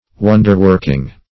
wonderworking - definition of wonderworking - synonyms, pronunciation, spelling from Free Dictionary